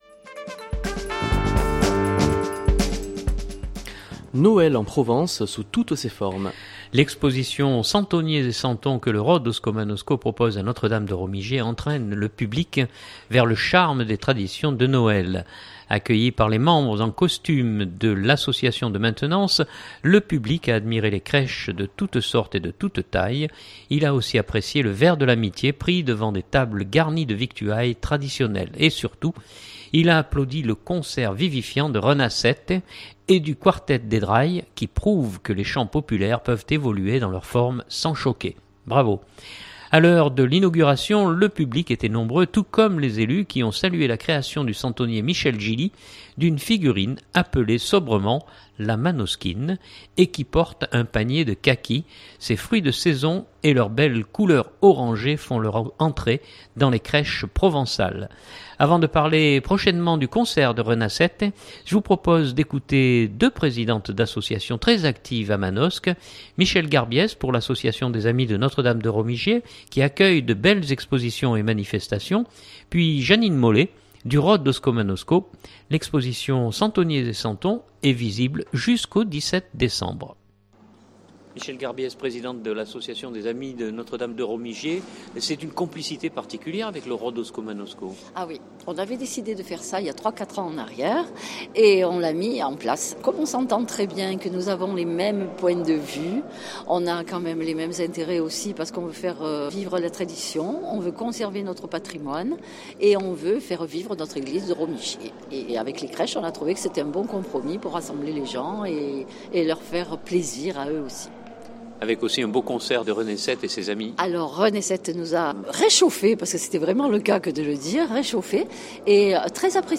je vous propose d’écouter deux présidentes d’associations très actives à Manosque